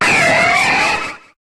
Cri de Pingoléon dans Pokémon HOME.